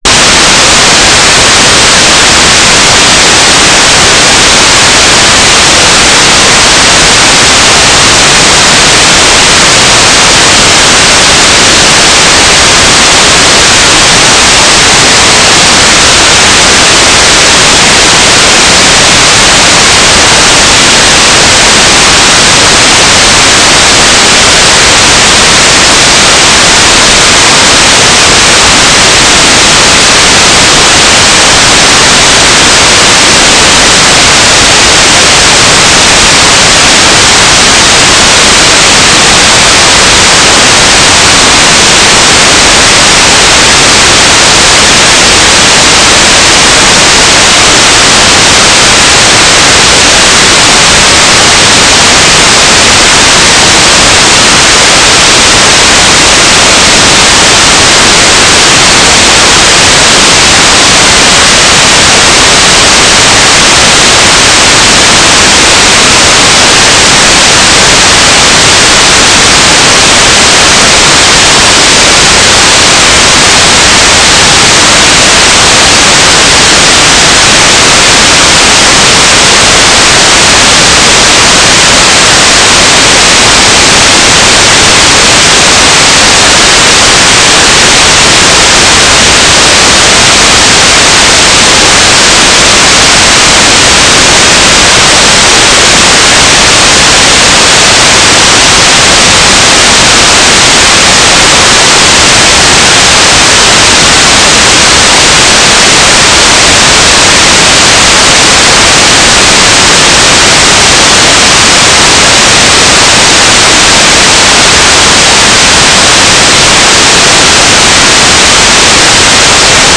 "transmitter_baud": 9600.0,